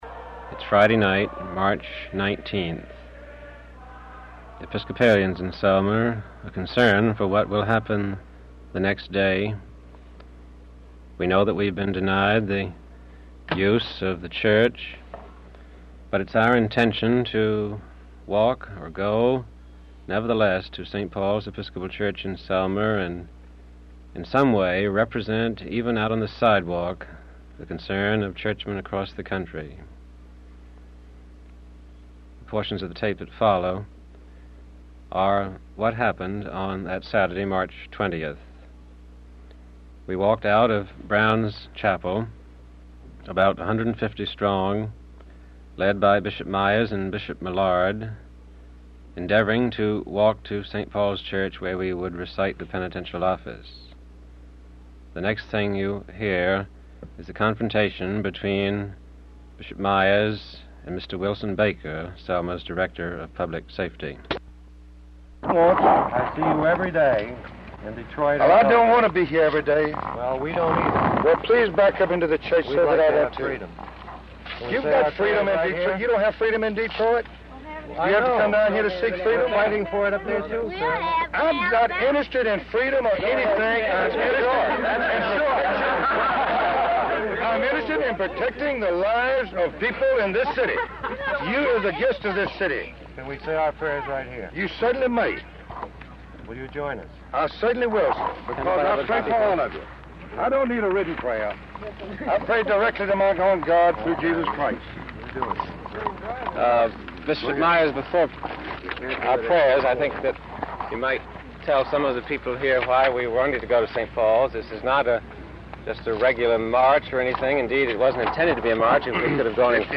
a March 1965 radio program